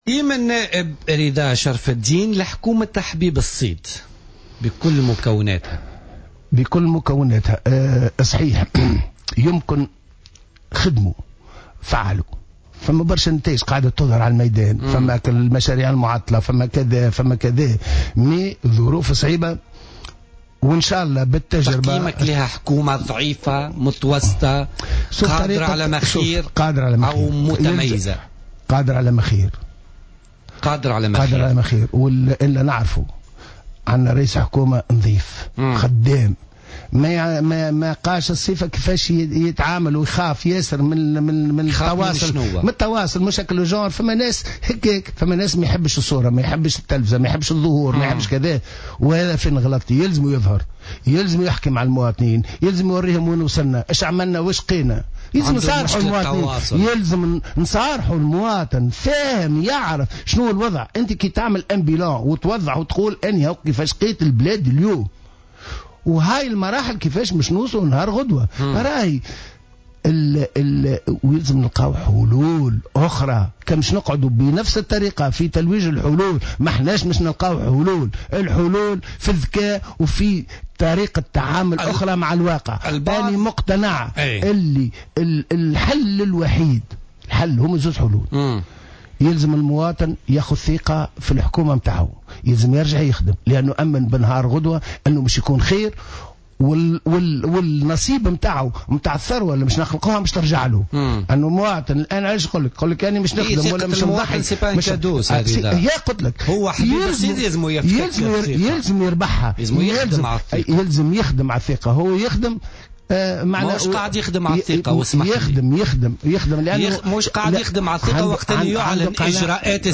وأضاف شرف الدين، ضيف برنامج "بوليتيكا" اليوم الثلاثاء : " الحبيب الصيد "نظيف" و"خدّام" لكن خطأه الوحيد هو عدم رغبته في الظهور الإعلامي و الحديث عن عمل حكومته وعن الإرث الثقيل الذي ورثته عن الحكومات السابقة".